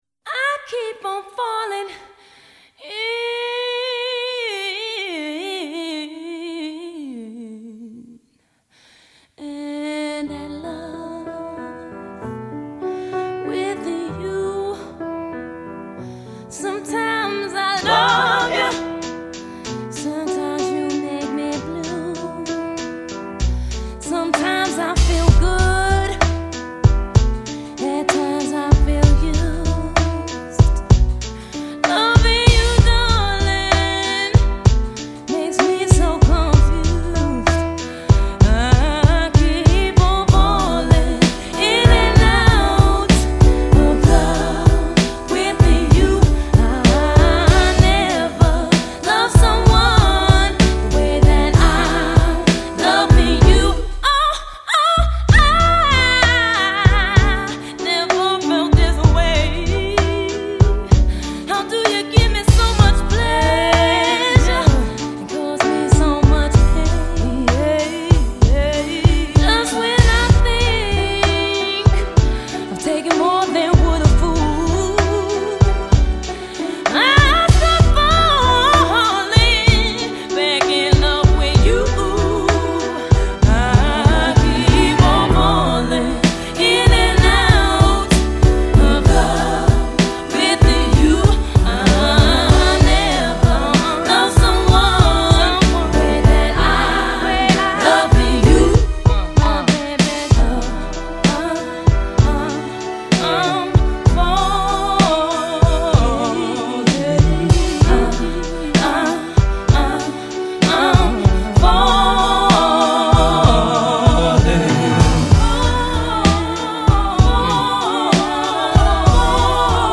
R&B Piano pop